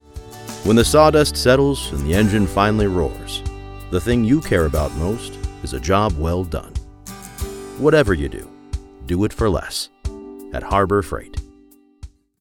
Male
Harbor Freight Internet Ad
Words that describe my voice are conversational, trustworthy, authoritative.